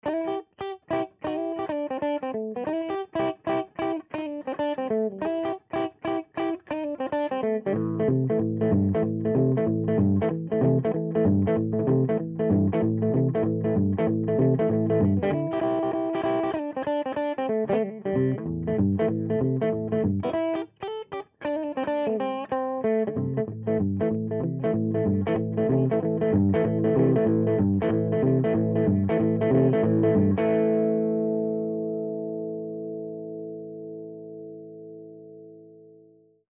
Les Paul: 1993 Gibson Les Paul Standard (Stock). Both pickups selected with volume and tone controls at maximum. Standard tuning.
Guitar is connected directly to the TubeUlent
Cabinet: Marshall JCM Lead Series 2 x 12 cabinet w/stock celestions
Mic: Rodes NT-5 Condenser Mic
Sound Samples recorded at Waterfront Productions
LP_Drive_Min.mp3